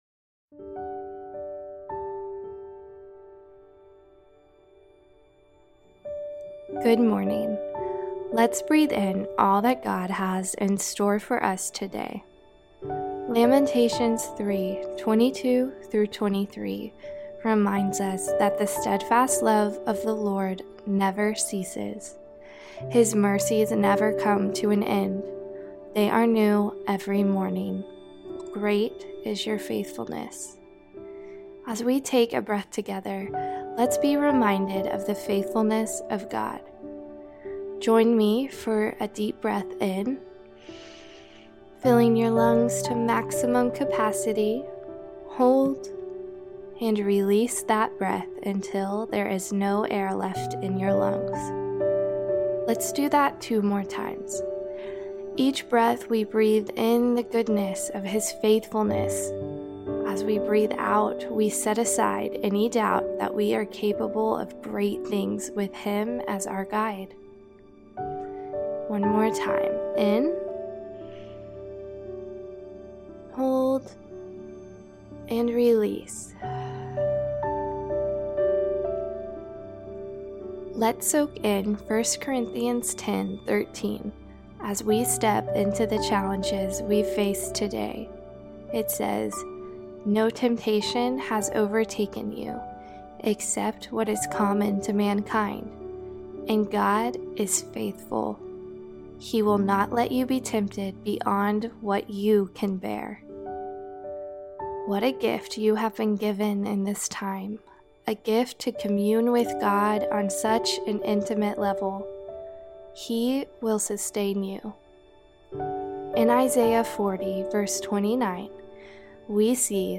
When I Rise is the Scripture based meditation podcast that guides your thoughts and breath to Truth.